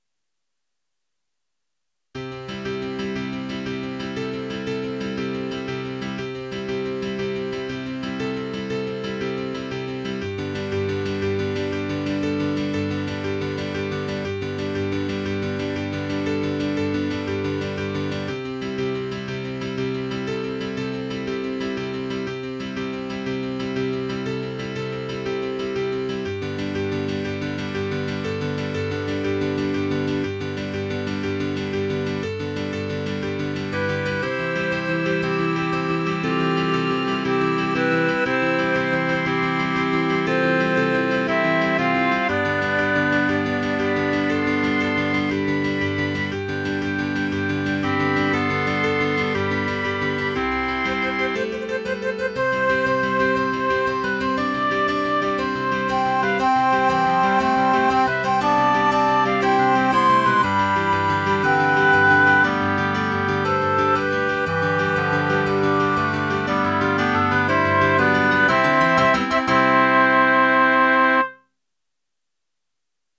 Tags: Piano, Clarinet, Woodwinds